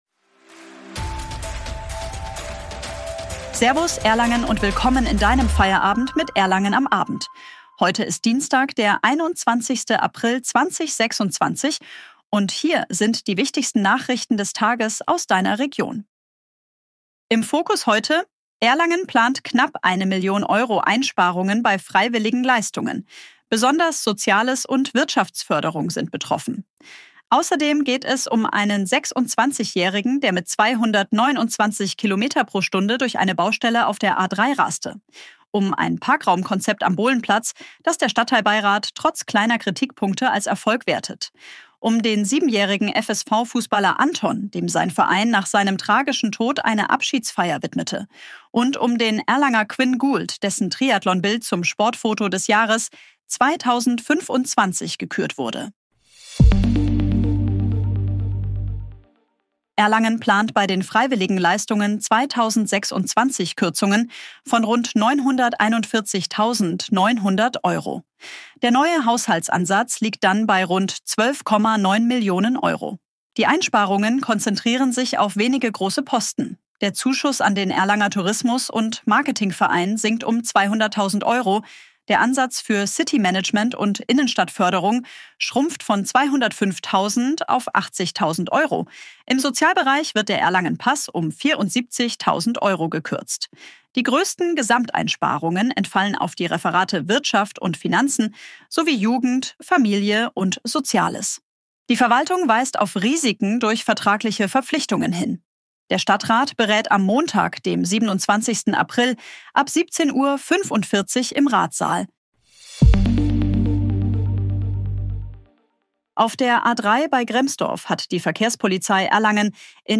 Guten Abend Erlangen! Willkommen zu deinem täglichen News-Update